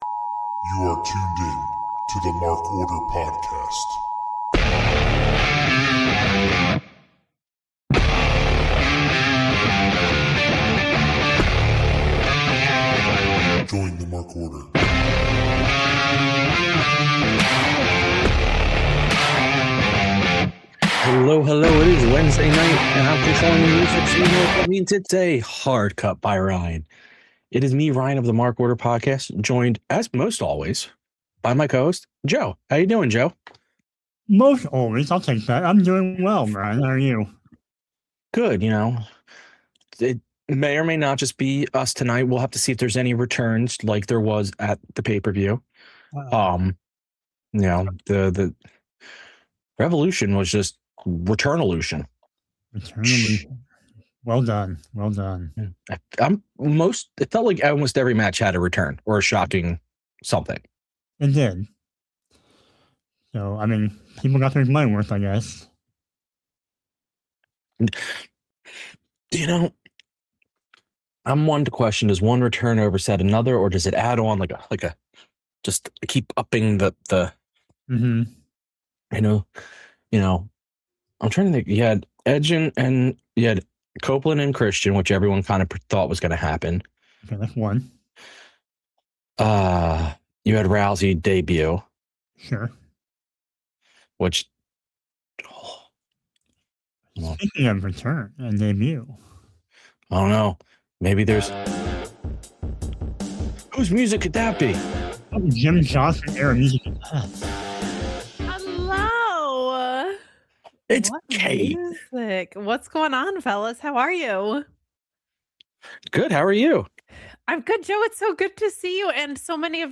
All three members are on the pod tonight. They talk about Revolution and then move into their Dynamite review.